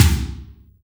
ODD TOM LO1.wav